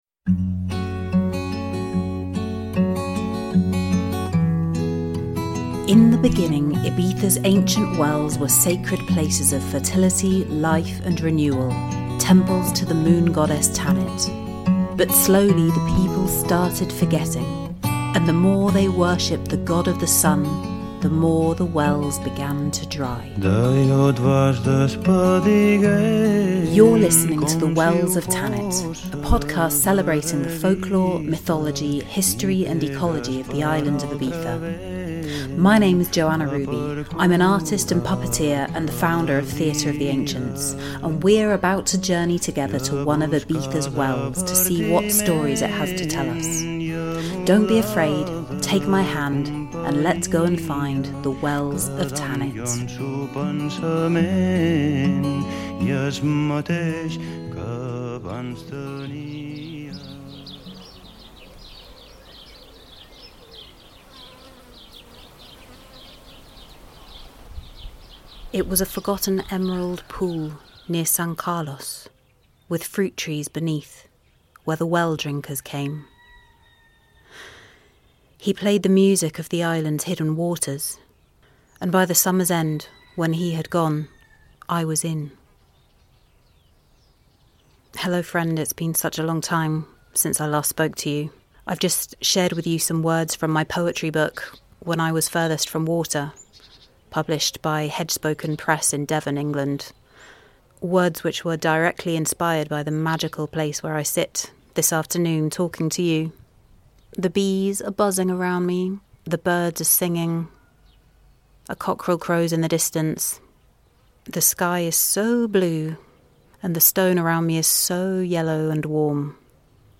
Join me today at the Font d'Atzaró spring, a hidden gem deep in the countryside of San Carles, North East Ibiza, where we explore that humble, universally-recognised food staple of bread as a symbol of sustenance, community, fertility... and man's timeless relationship with the cycles of nature, of life and death.